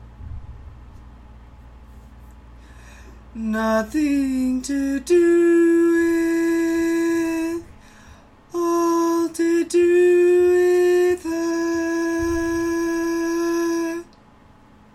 Key written in: C Major
Type: Barbershop
Each recording below is single part only.